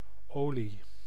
Ääntäminen
Synonyymit essence baume grosse légume Ääntäminen France: IPA: [ɥil] Haettu sana löytyi näillä lähdekielillä: ranska Käännös Ääninäyte Substantiivit 1. olie {f} Muut/tuntemattomat 2. hoge ome Suku: f .